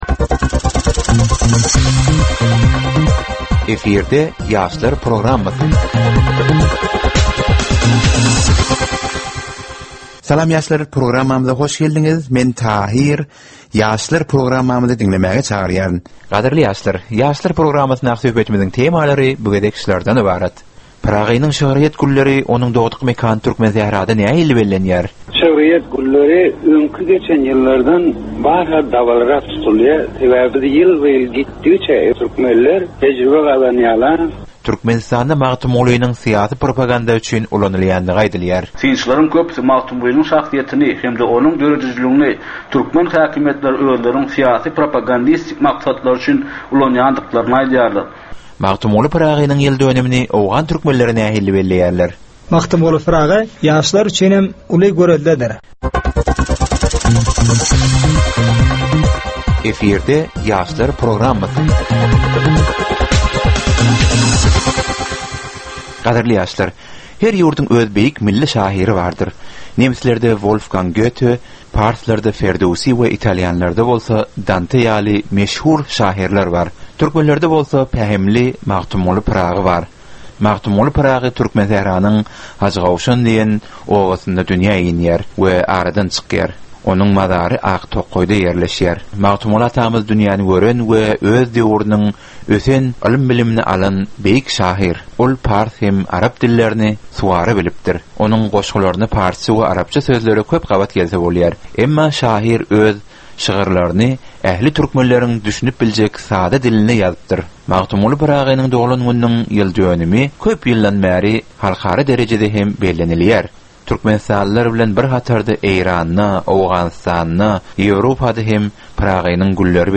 Türkmen we halkara yaşlarynyň durmuşyna degişli derwaýys meselelere we täzeliklere bagyşlanylyp taýýarlanylýan ýörite gepleşik.
Gepleşigiň dowamynda aýdym-sazlar hem eşitdirilýär.